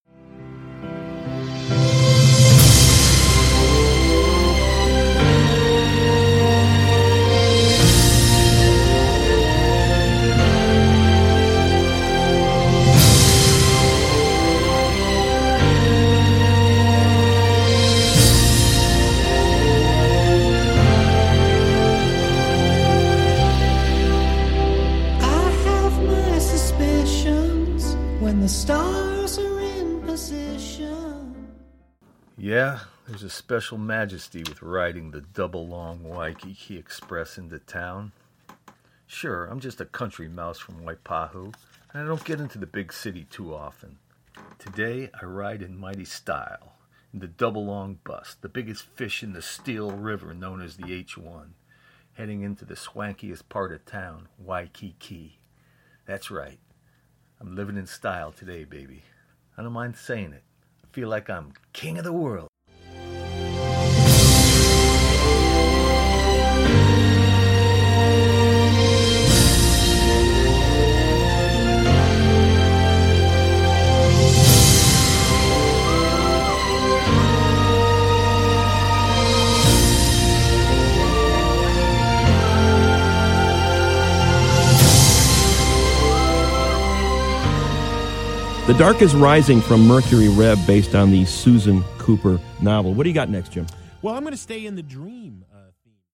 I’m riding the Waikiki Express into town at 07:10 AM.
Riding the Waikiki Express into town
bus-ride-final.mp3